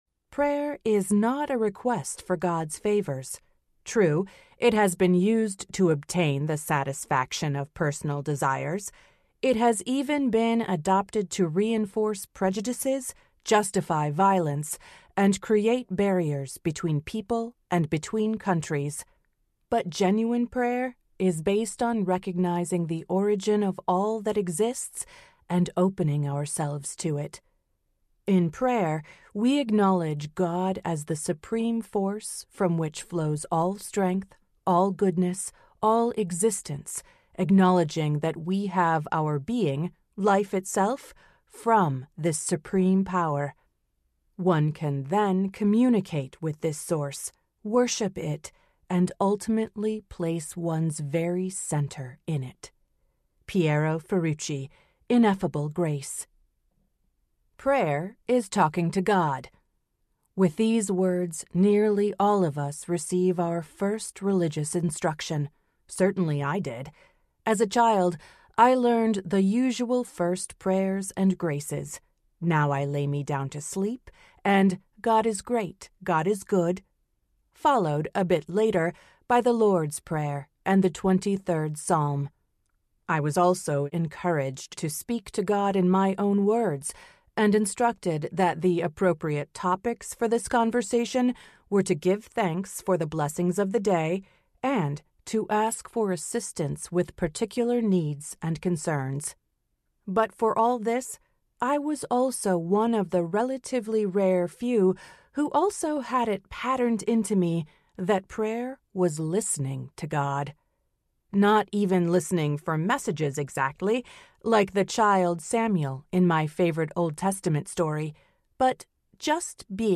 Centering Prayer and Inner Awakening Audiobook
Narrator
6.8 Hrs. – Unabridged